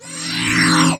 SpellCreate.wav